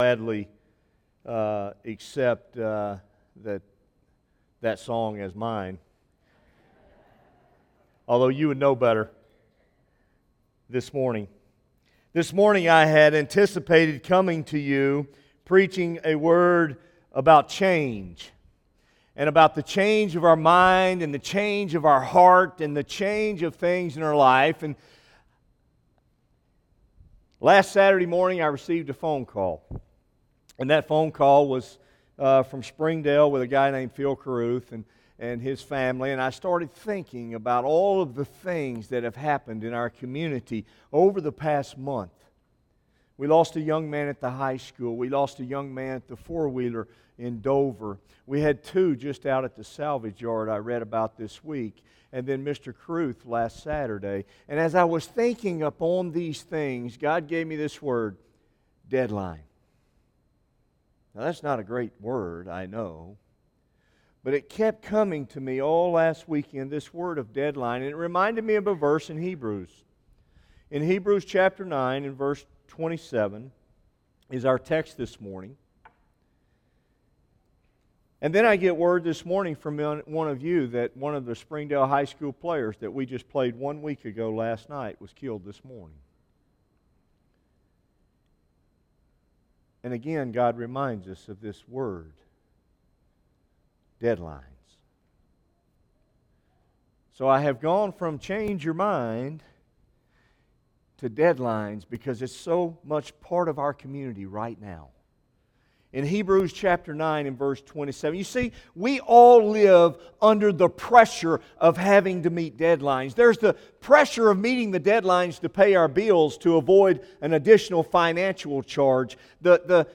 by Office Manager | Sep 26, 2016 | Bulletin, Sermons | 0 comments